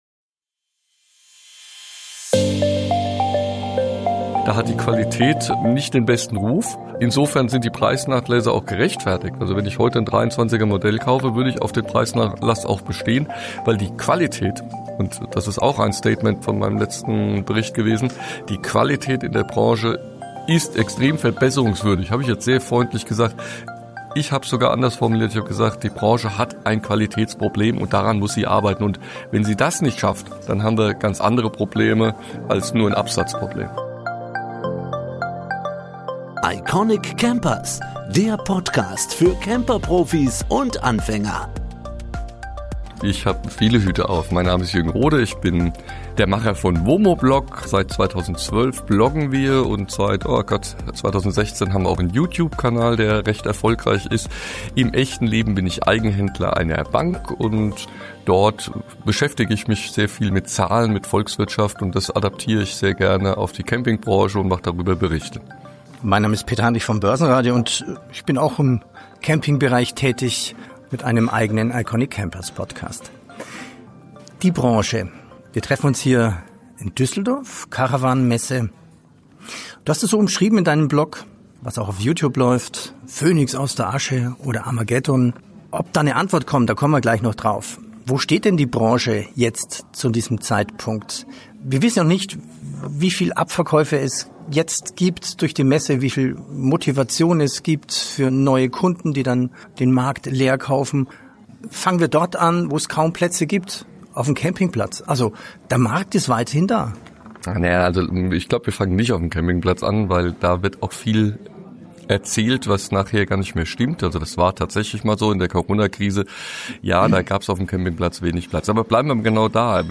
Interview auf dem Caravan Salon